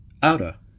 Ääntäminen
Vaihtoehtoiset kirjoitusmuodot (harvinainen) outa Ääntäminen UK : IPA : /ˈaʊtə/ US : IPA : [ˈaʊɾə] Canada: IPA : [ˈʌʊɾə] Southern England CA : IPA : [ˈʌʊɾə] UK : IPA : [ˈaʊʔə] US : IPA : [aːɾə] IPA : [ˈæːɾə]